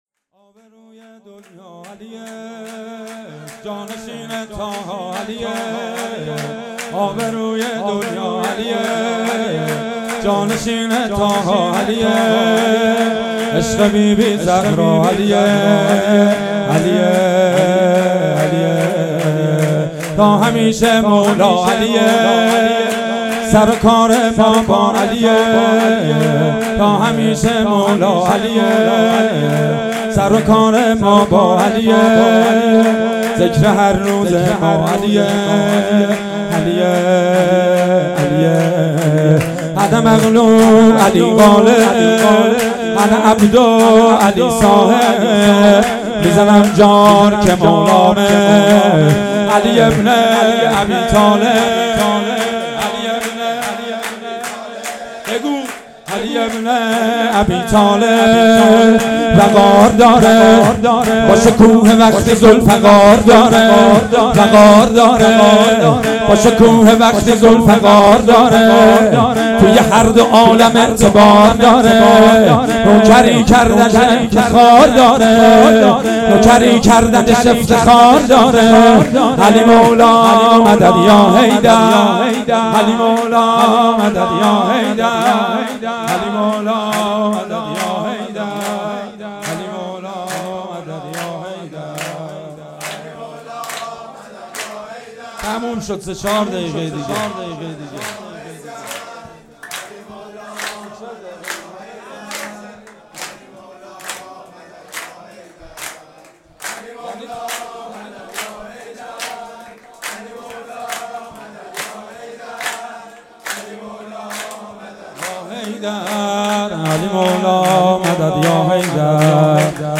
جشن عید سعید غدیر